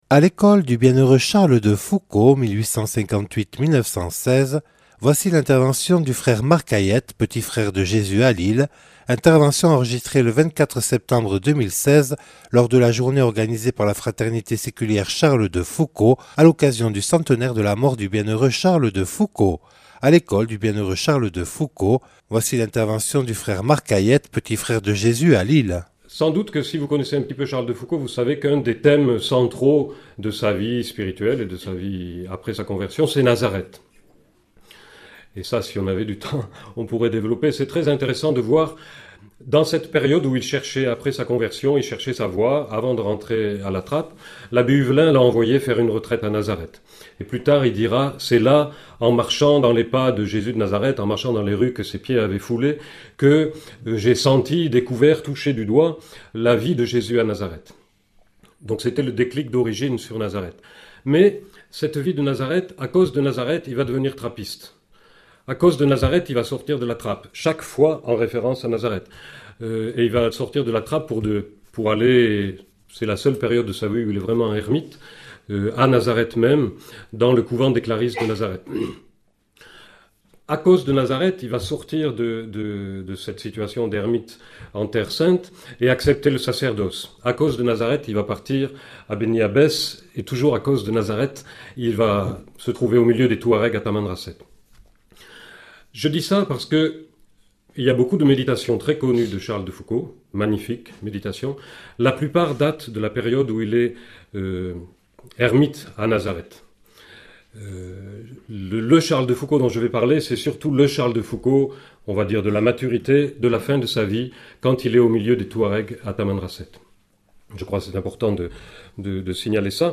(Enregistré le 24/09/2016 à l’abbaye de Belloc).